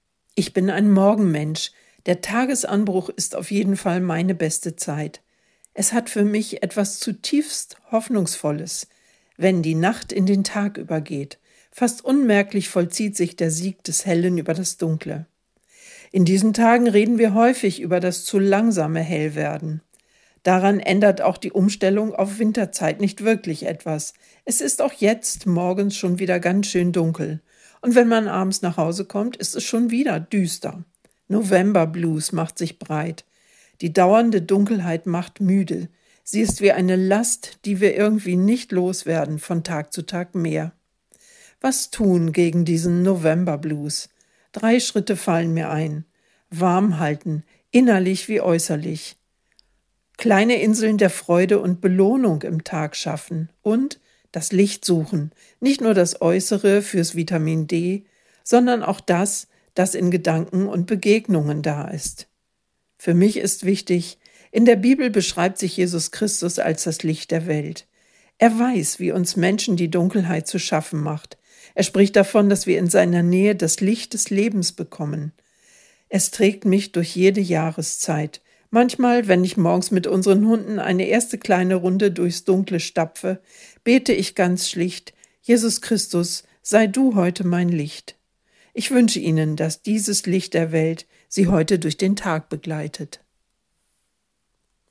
Radioandacht vom 6. November